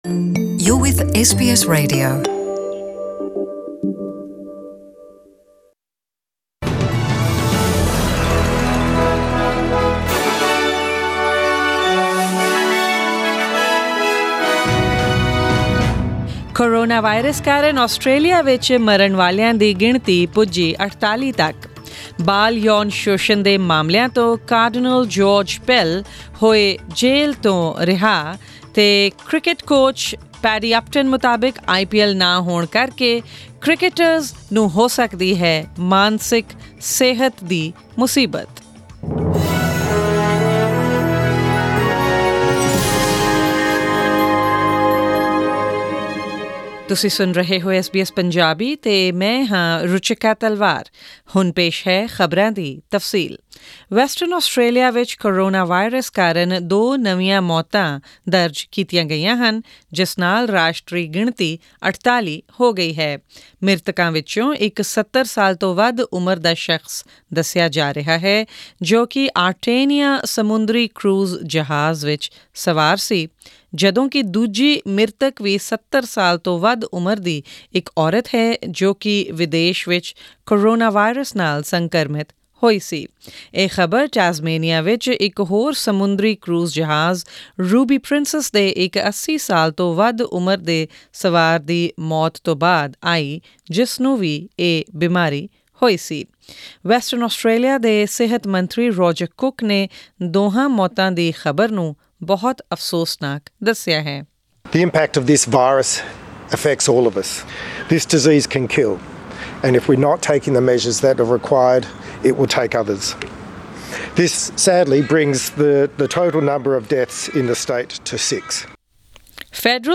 Australian News in Punjabi: 7 April 2020